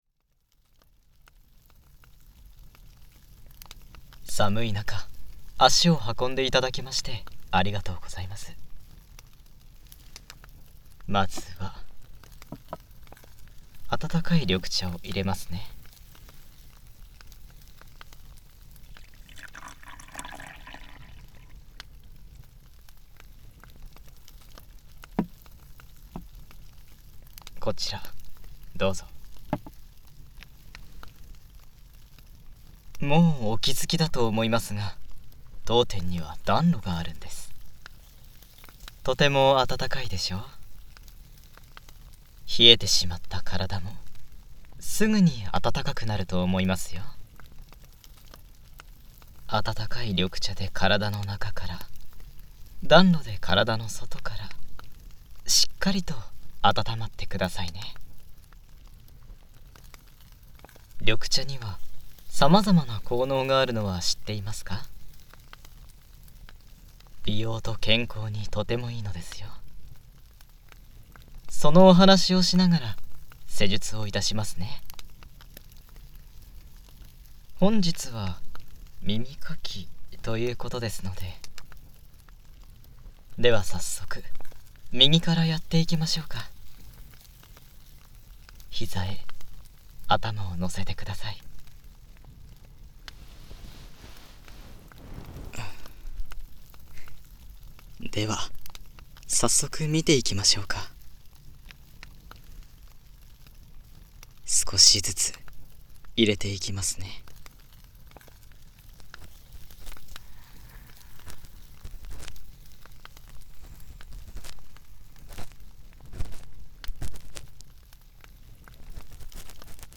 治愈 和服 掏耳 女性向 环绕音 ASMR
1_mp3僅掏耳棒_療癒店・焔.mp3